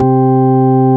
MELLOW 3.wav